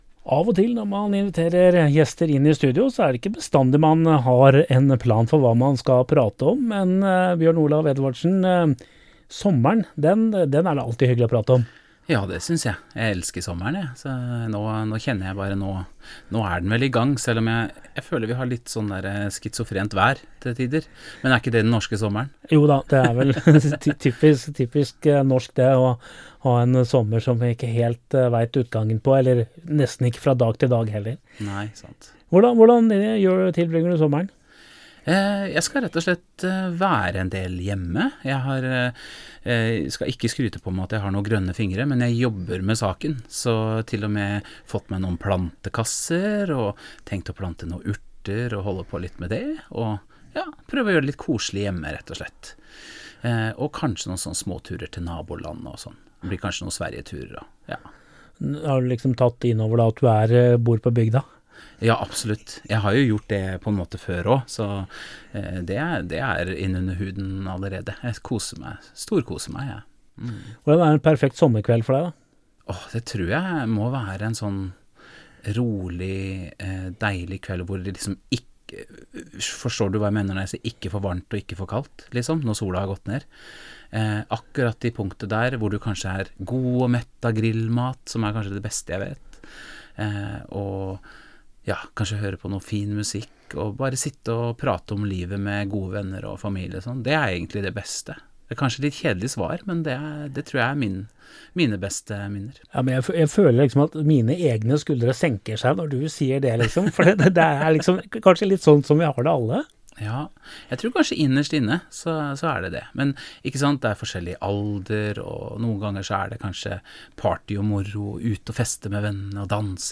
Hør sommerpraten